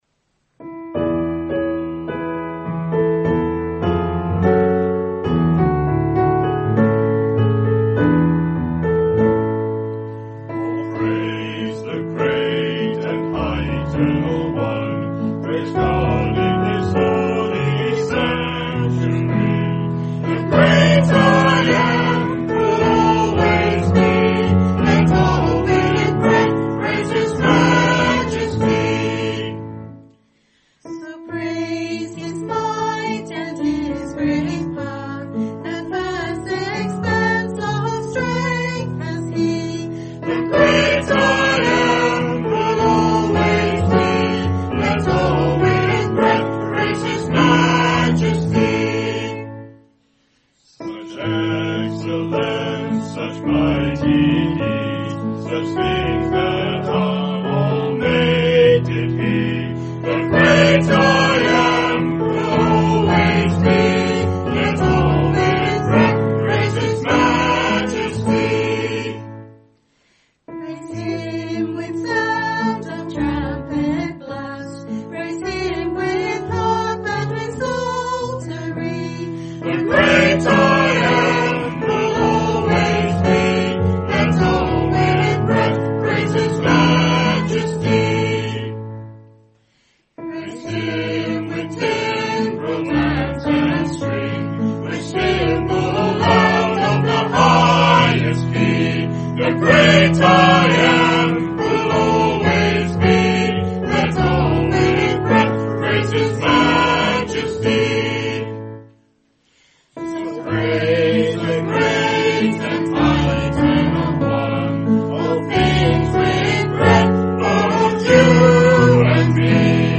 Special Music
by Festival Small Group   Psalm 150 - All Praise The Great And High Eternal One
sung Wales UK 14 Oct 2025